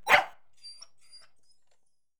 Bark3.wav